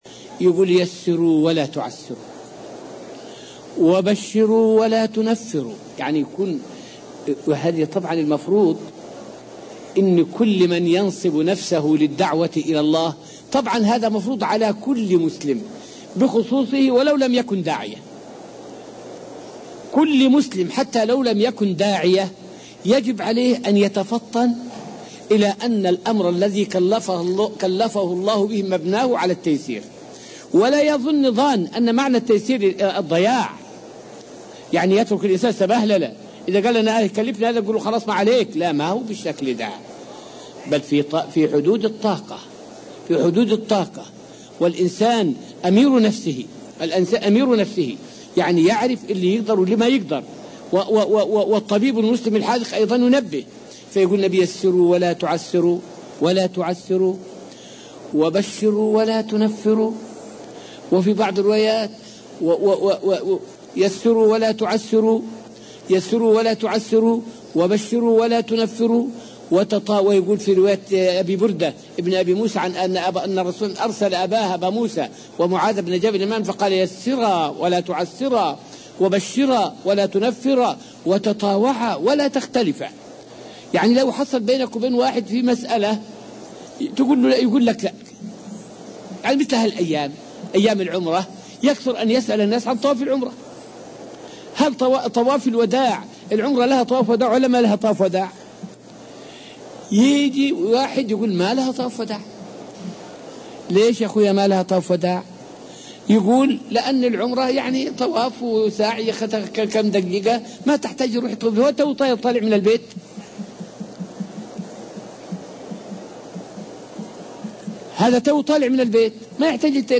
فائدة من الدرس الثامن عشر من دروس تفسير سورة البقرة والتي ألقيت في المسجد النبوي الشريف حول أن التيسير في الدين لا يعني تضييع الدين.